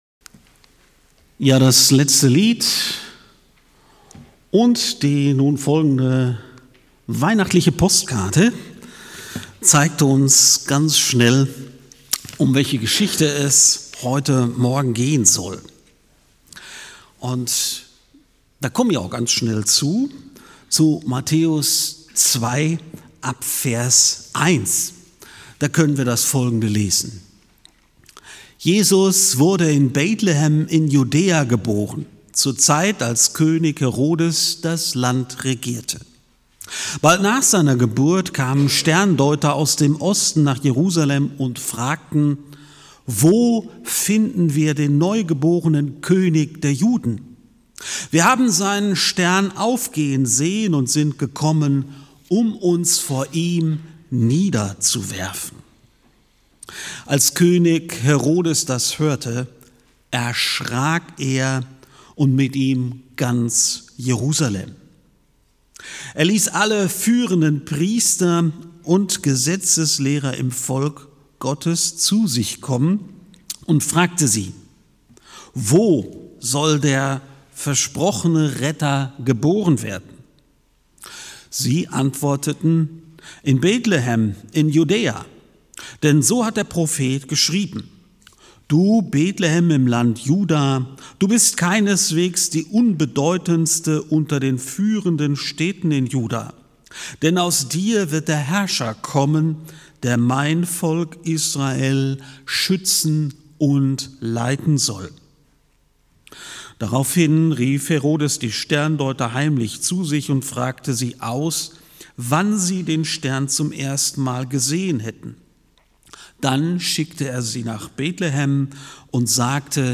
Die Karawane für den König [29.12.2024] ~ FeG Aschaffenburg - Predigt Podcast